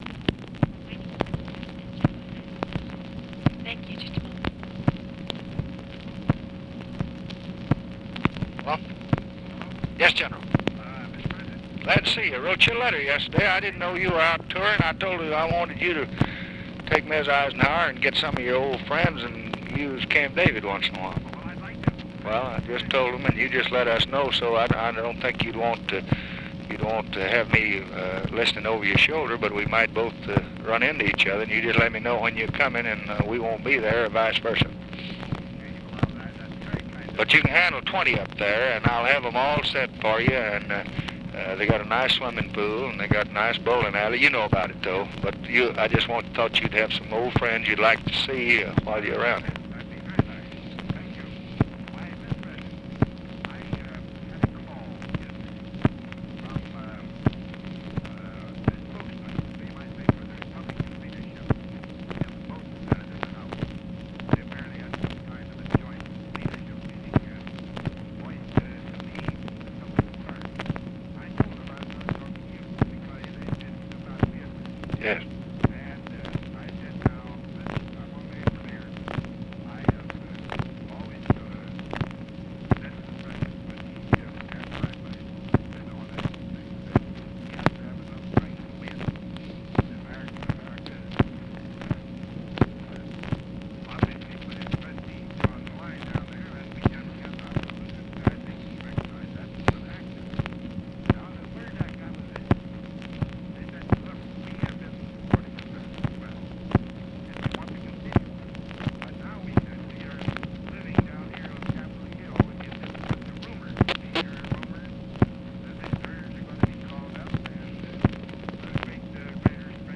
Conversation with DWIGHT EISENHOWER, July 23, 1965
Secret White House Tapes